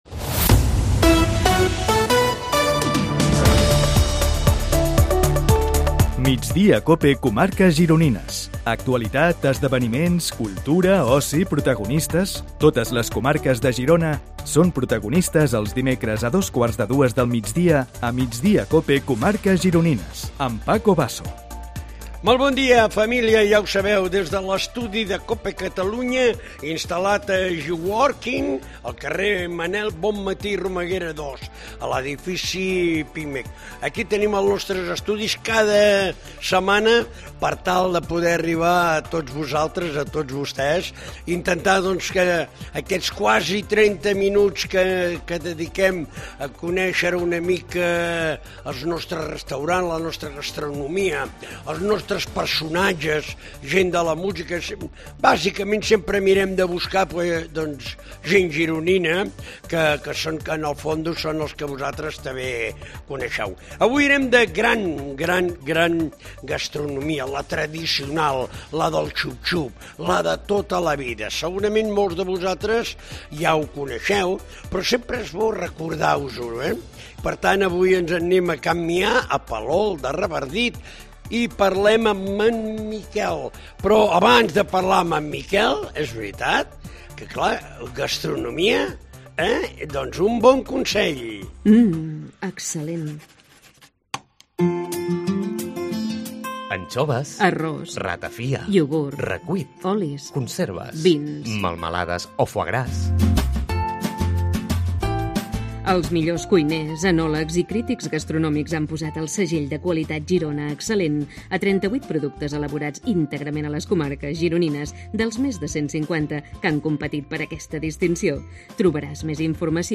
Entrevistem els protagonistes de l'actualitat a les comarques gironines
El nostre és un programa de ràdio que compte amb els millors ingredients.